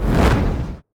fire2.ogg